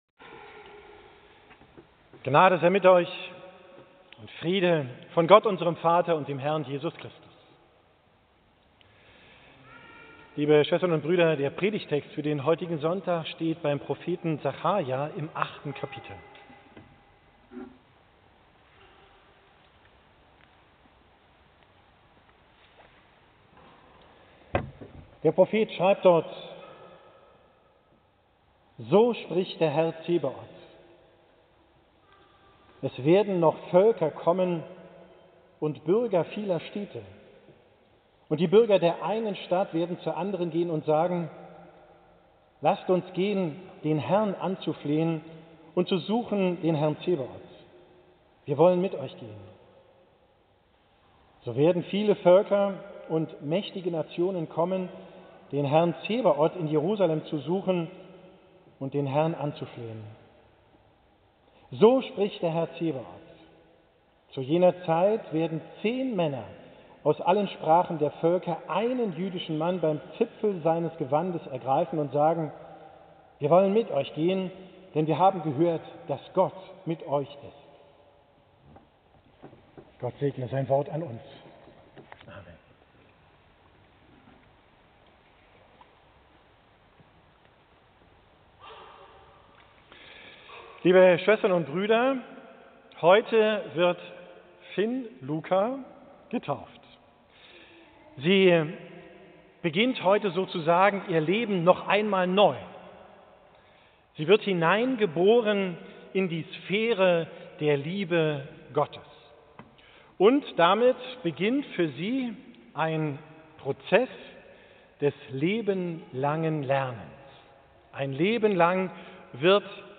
Predigt vom 10.